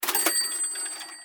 snd_item_buy.ogg